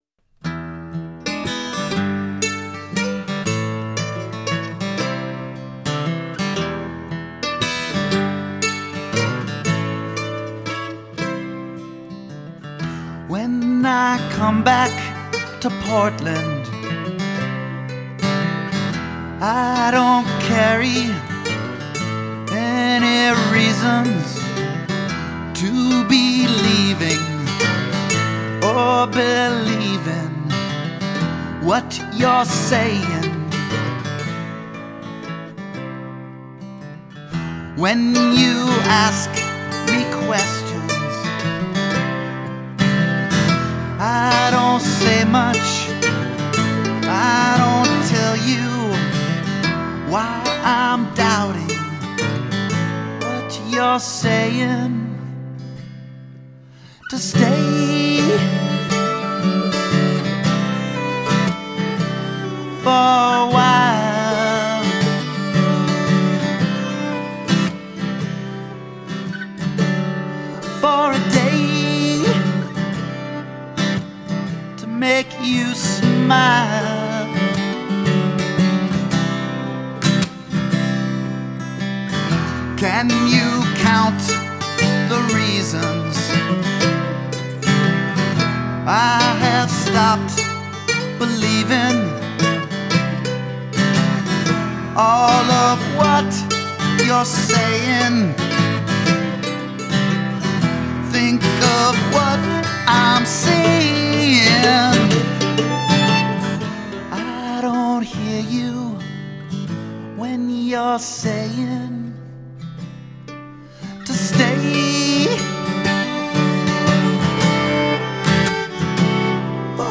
Guitar and vocals
Mandolin and fiddle